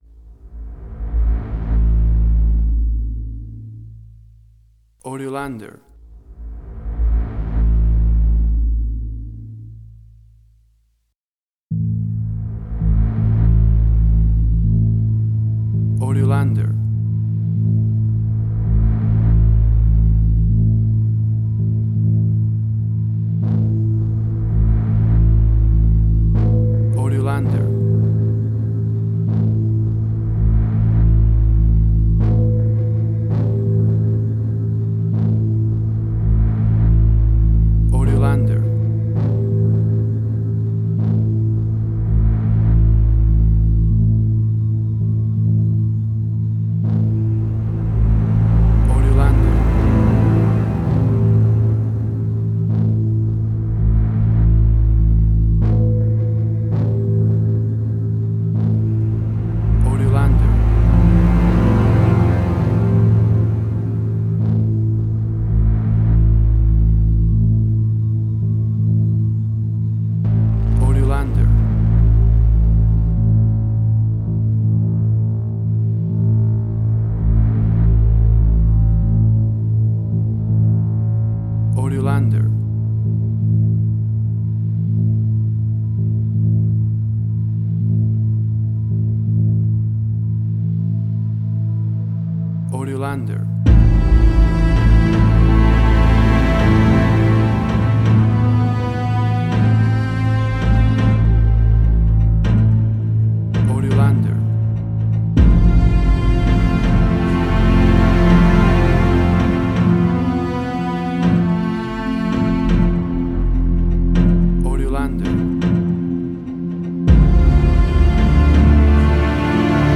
Post-Electronic.
Tempo (BPM): 82